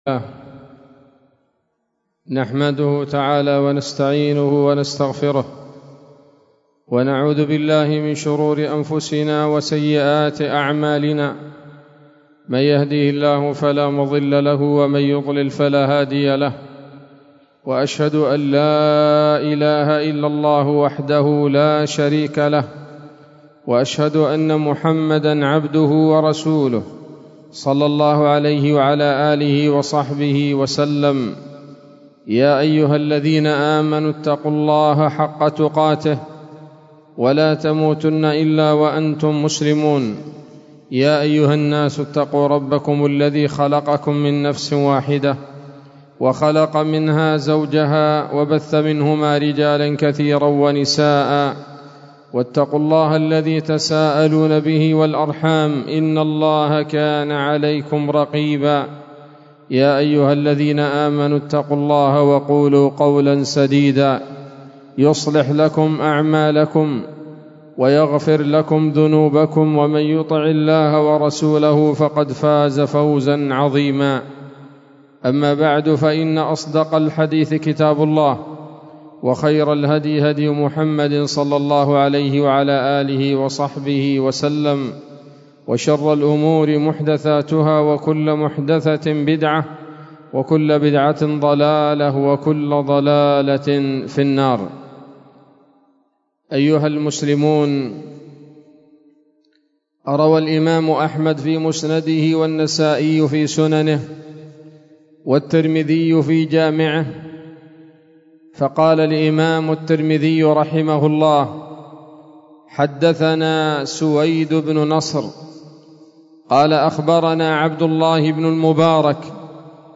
خطبة جمعة بعنوان: (( ذم الحرص على المال والجاه )) 22 شوال 1444 هـ، دار الحديث السلفية بصلاح الدين